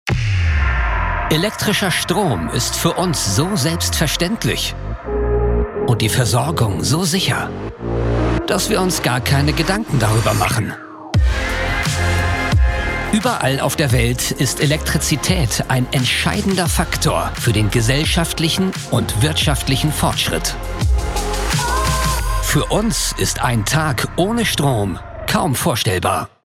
Commerciale, Cool, Mature, Amicale, Corporative
Corporate